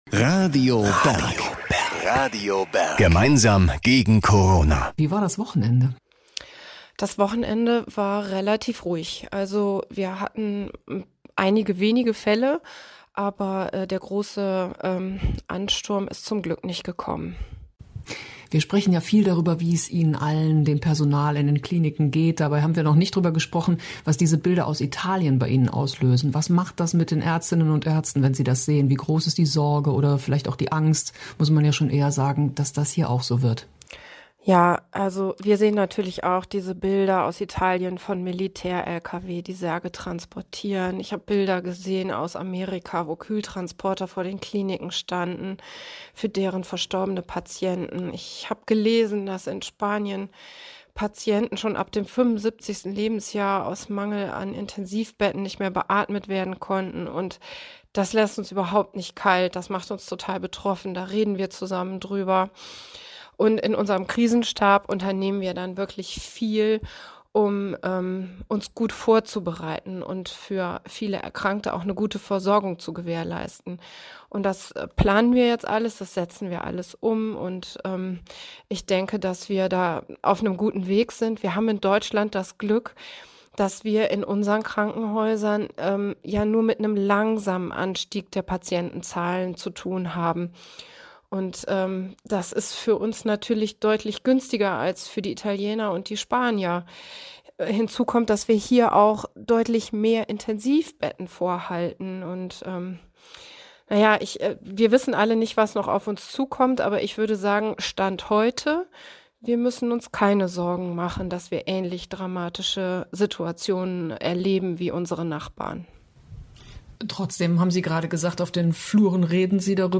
Die Interviews aus 2020